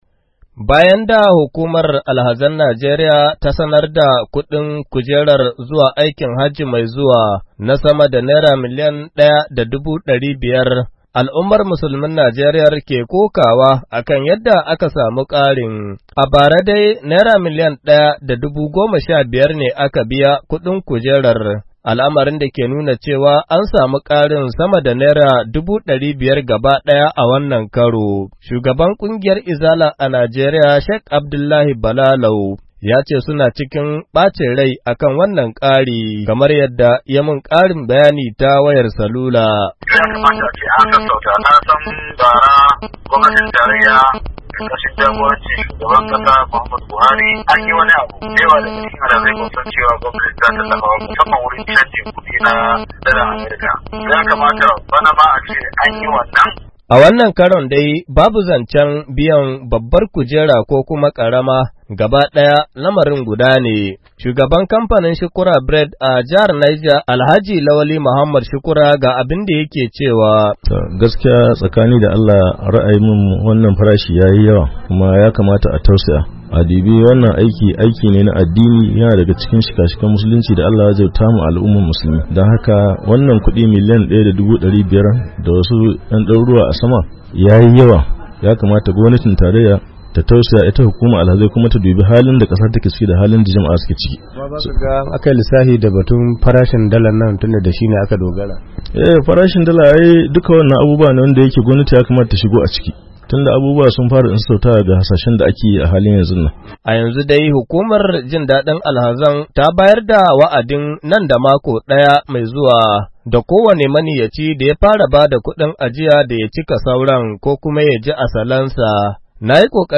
Domin karin bayani ga rahotan